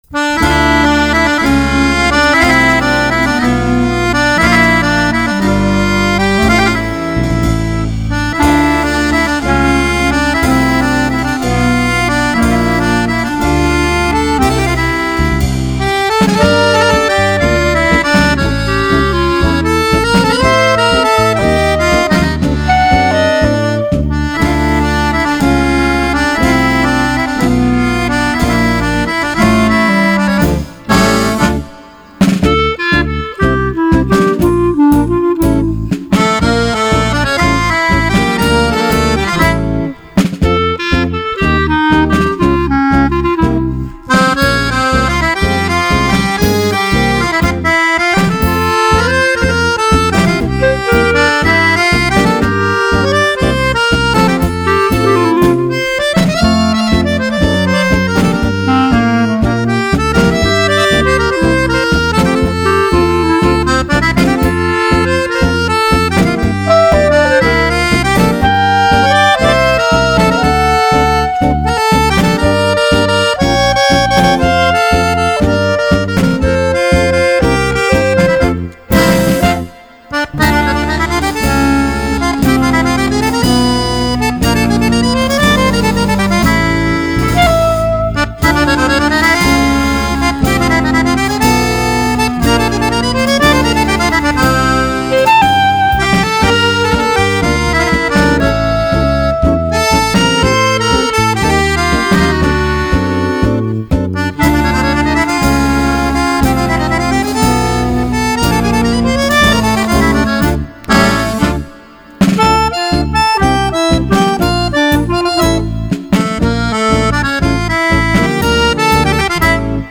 TANGO ITALIANO
Fisarmonica, Clarinetto